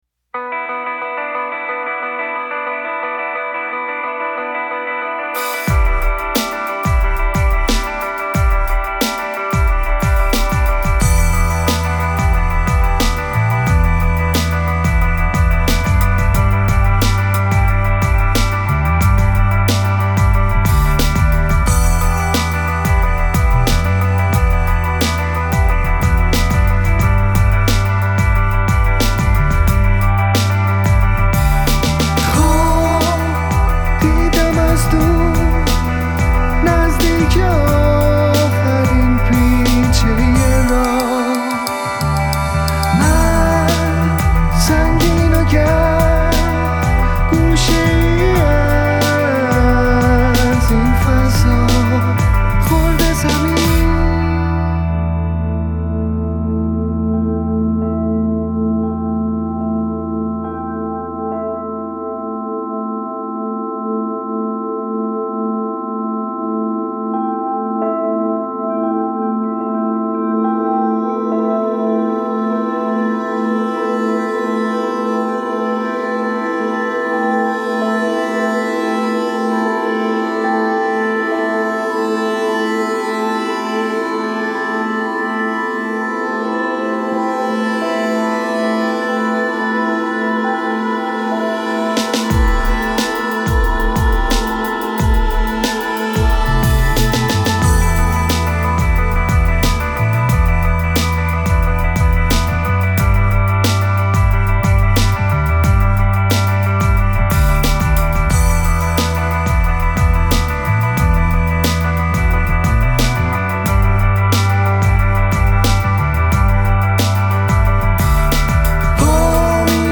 • Genre:Rock, Alternative Rock